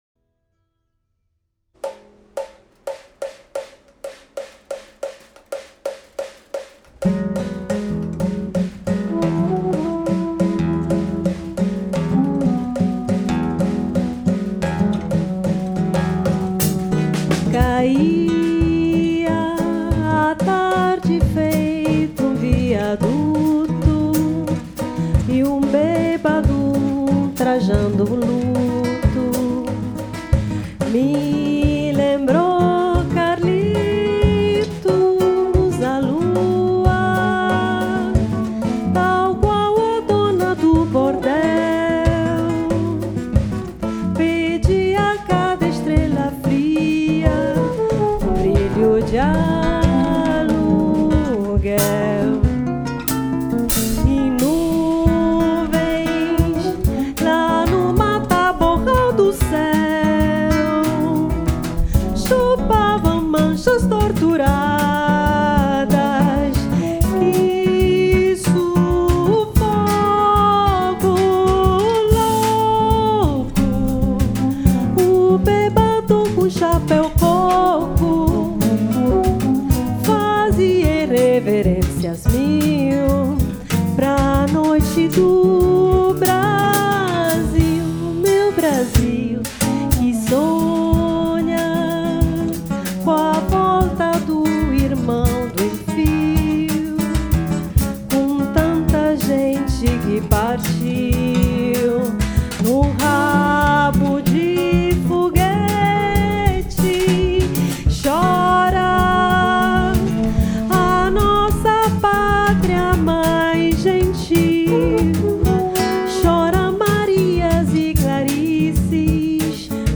Peça de confronto - canto